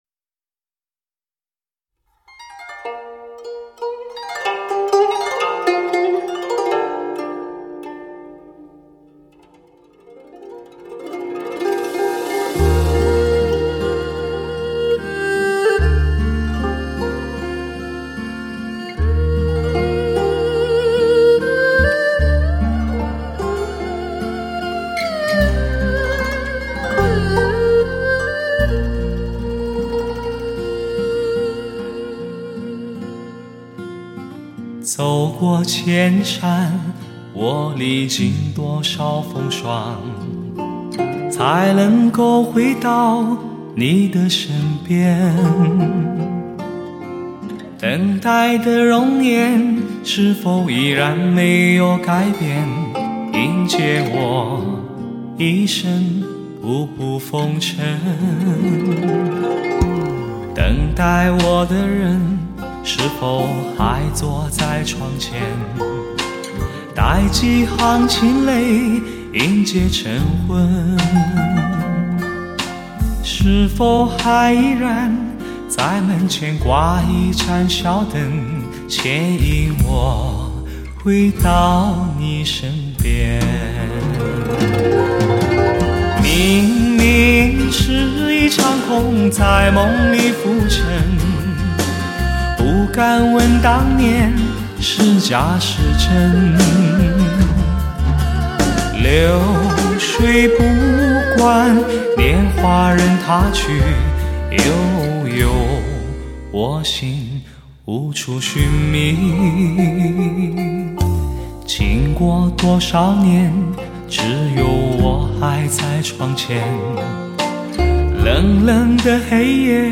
史上最具HI-FI震撼的发烧男声
技惊四座的演唱，震撼全场的音效，吸引所有目光的美妙靓声
新一代黑胶高保真珍藏CD